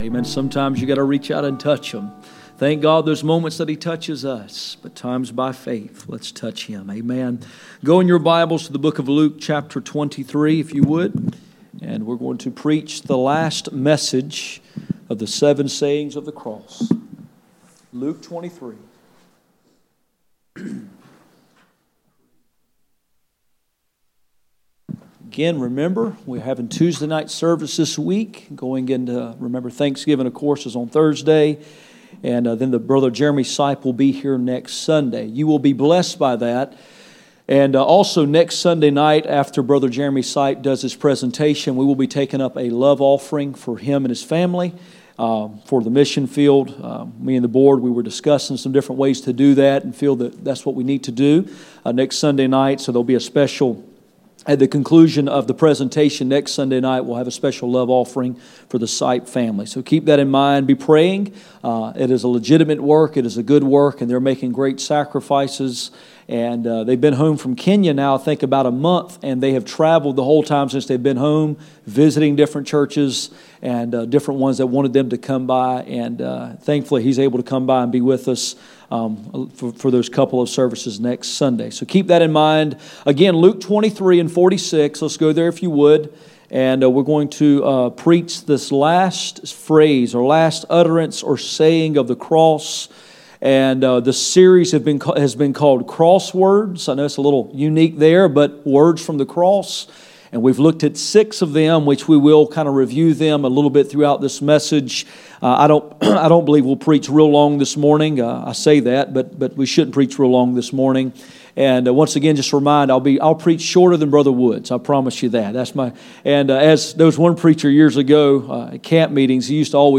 Passage: Luke 23:46 Service Type: Sunday Morning %todo_render% « Cross Words